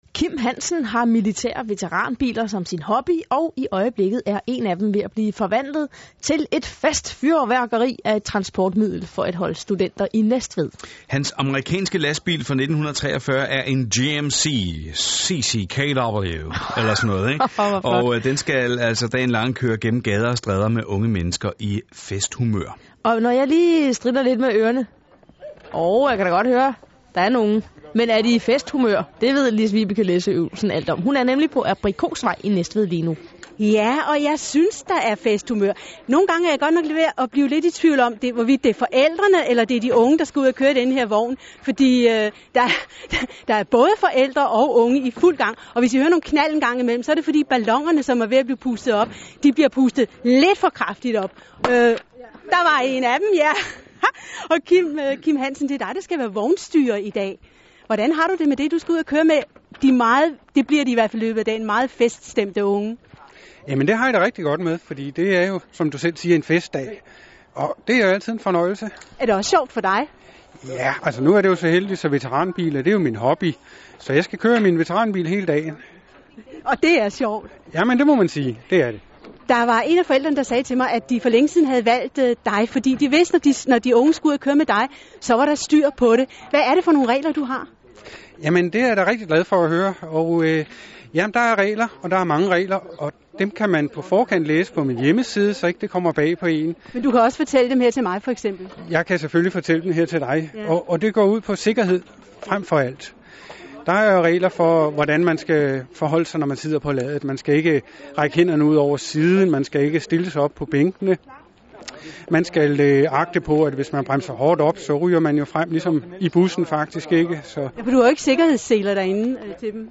DR P4 havde med vanlig sans for friske lokale nyheder et reportagehold klar da vi startede torsdag morgen. Der blev sendt direkte til hele Sjælland mens vognen blev pyntet.
DRP4interview.mp3